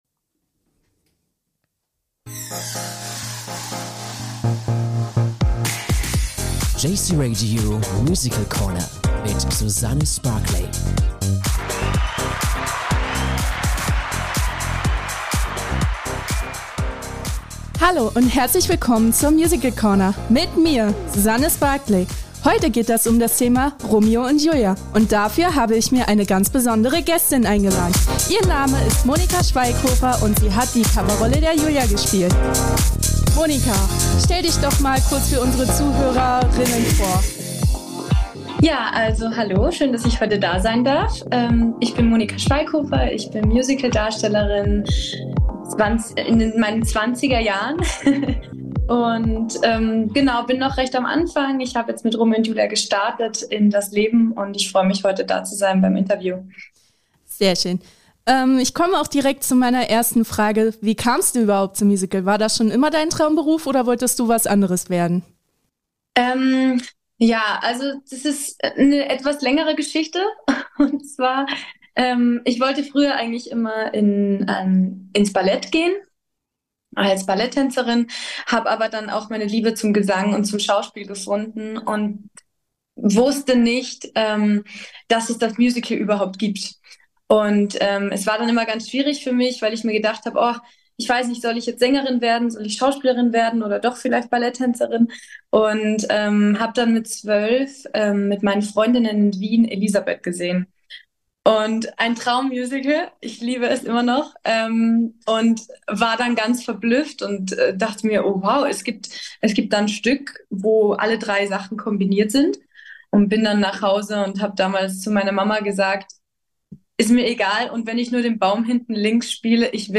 Musical-Corner: Im Gespräch